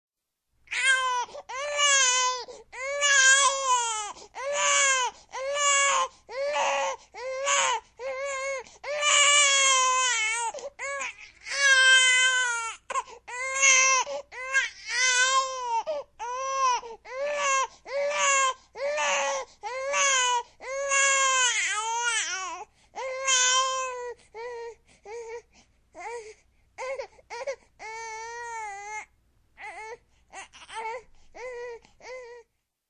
新生婴儿哭声音效_人物音效音效配乐_免费素材下载_提案神器
新生婴儿哭声音效免费音频素材下载